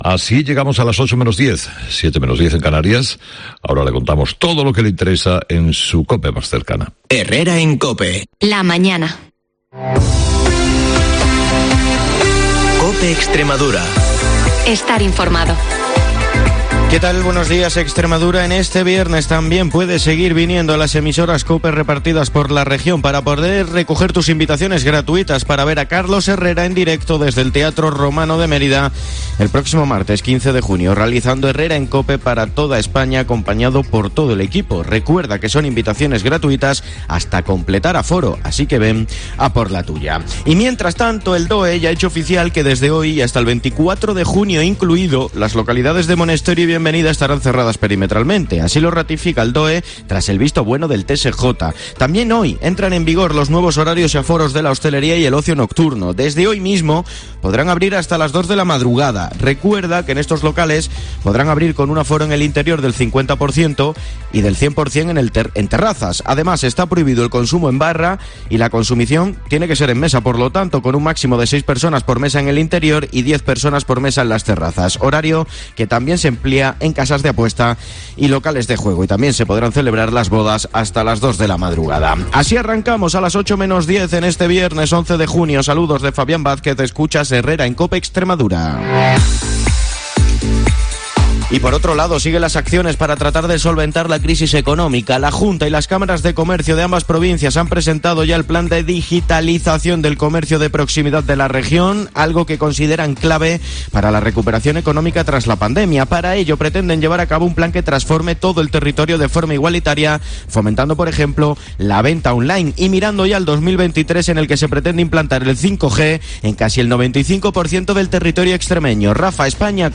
te contamos la última hora de Extremadura en Herrera en COPE, el informativo líder de la radio en la región